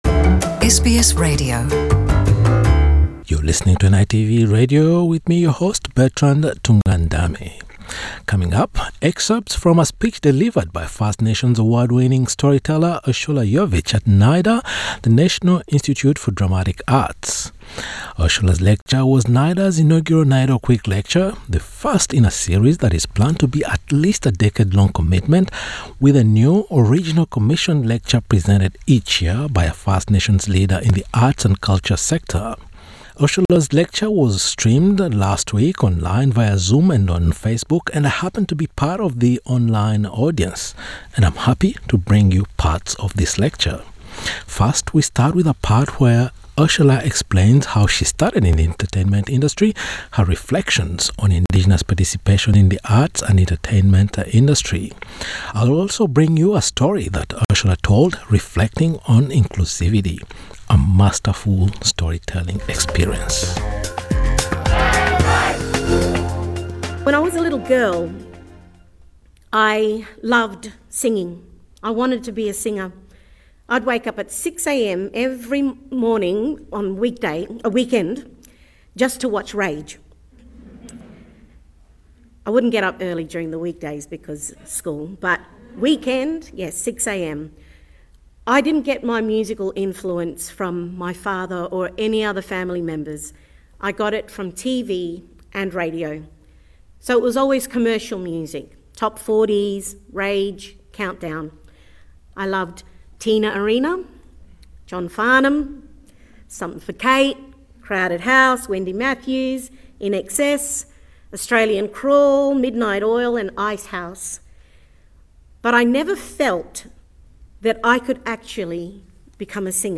Ursula Yovich was selected to deliver NIDA’s inaugural NAIDOC Week lecture. In her speech, streamed via Zoom and Facebook, the awarding winning artist reflected on Indigenous leadership and participation in preforming arts.